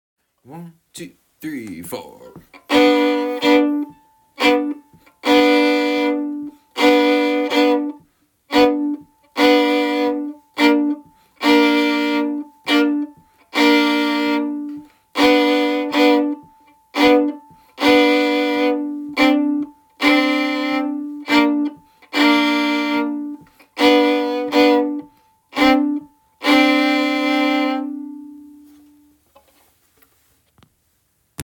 Milk Cow Blues comping (MP3)Download